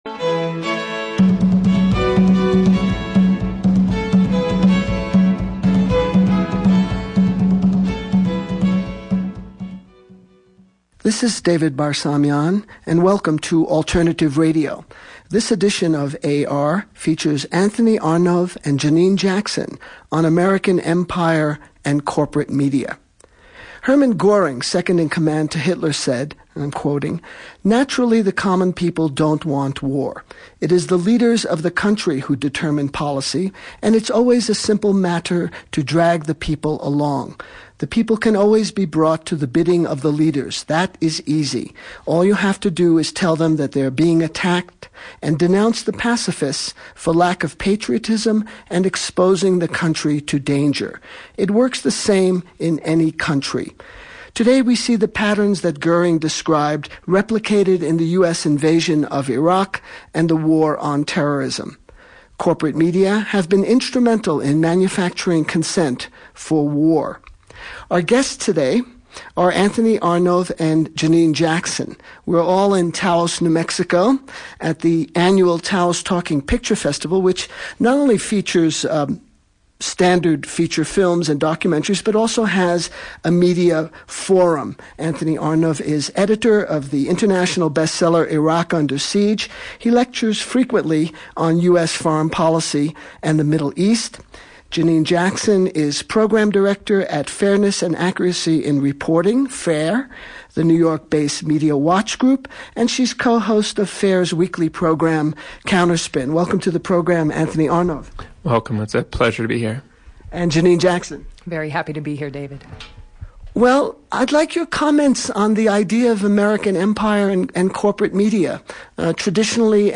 Alternative Radio interview